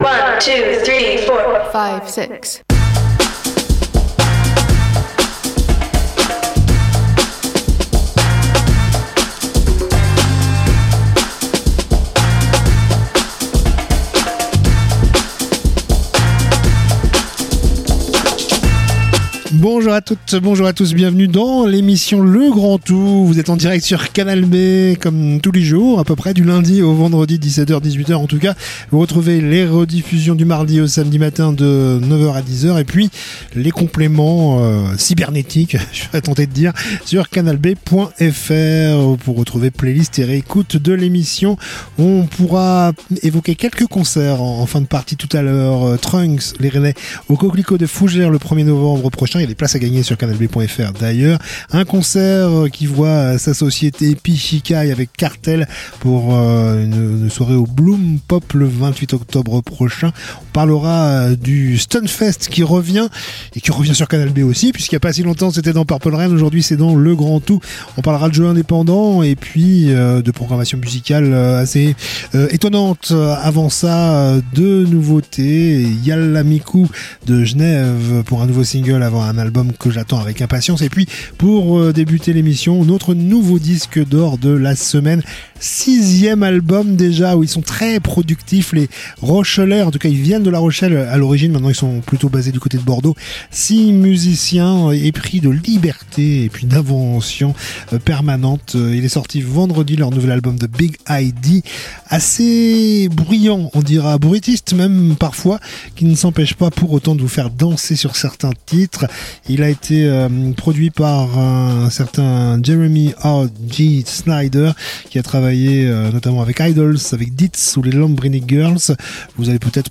itv